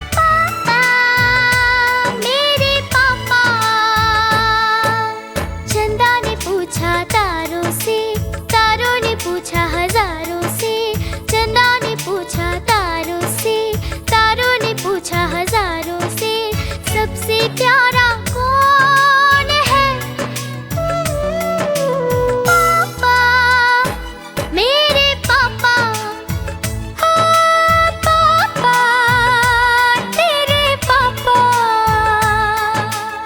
Hindi song
Categories: Hindi Ringtones